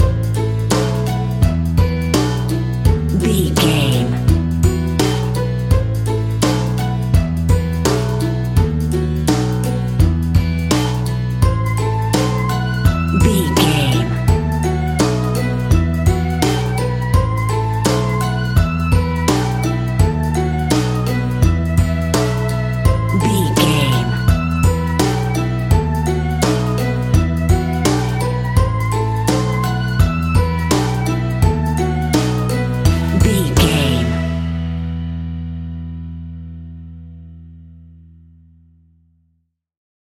Aeolian/Minor
childlike
happy
kids piano